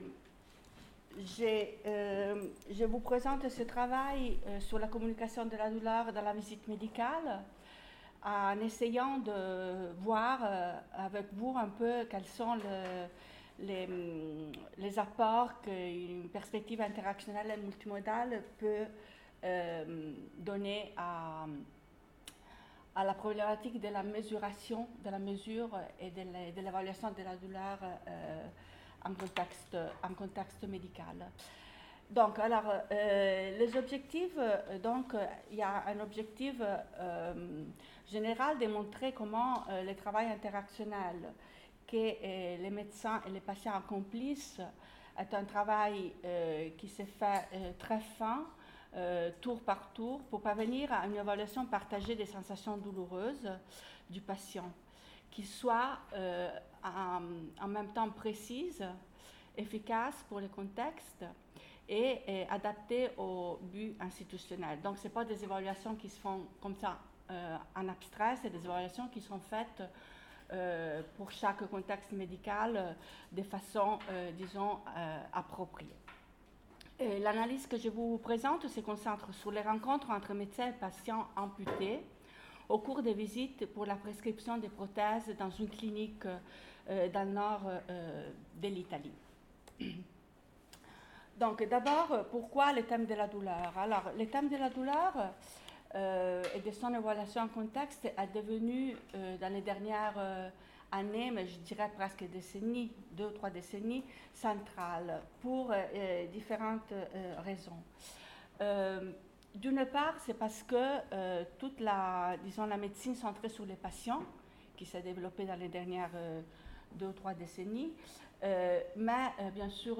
[Conférence]